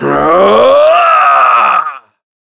Shakmeh-Vox_Casting2_a.wav